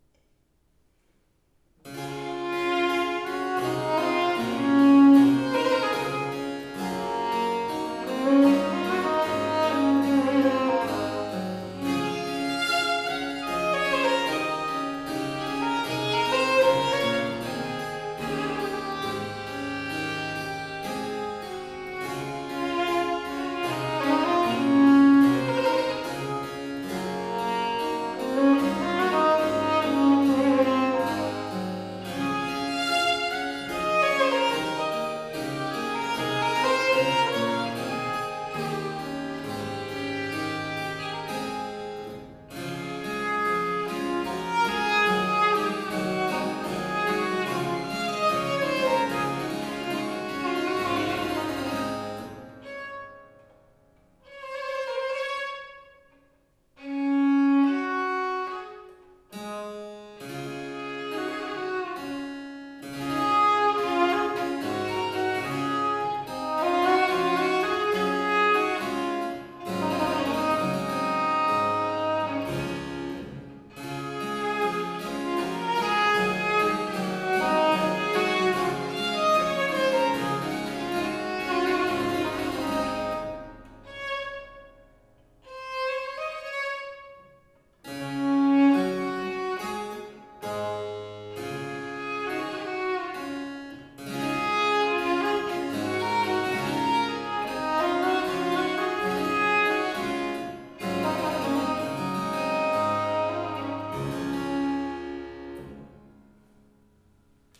Arrangement for violin
and harpsichord